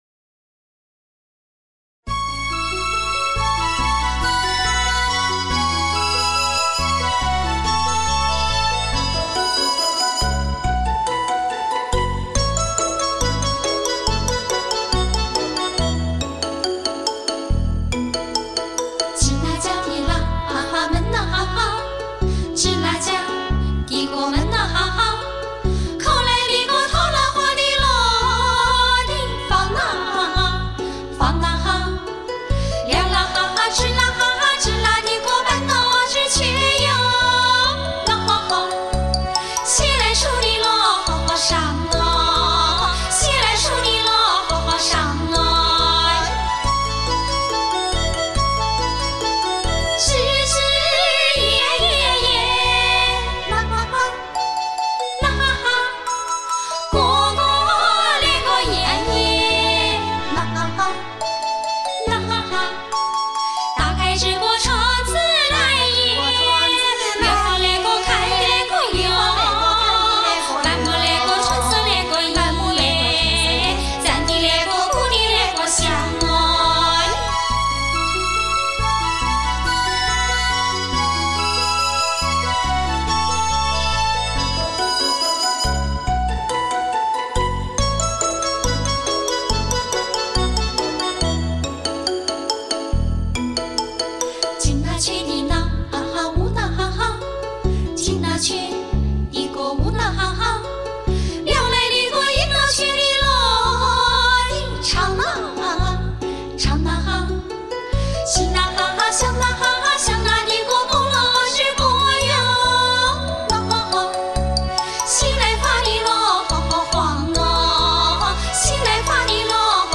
民歌
和县民歌